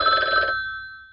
Old Phone Ring